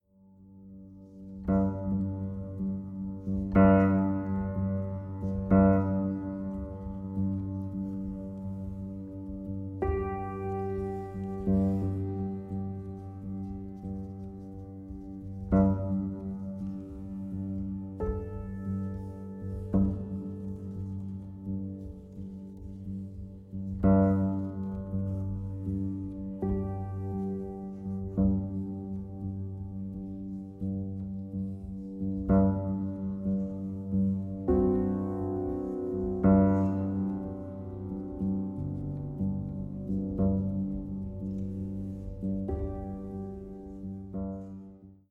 ミュートしたアップライトピアノの柔らかな音色が眠りへと誘います。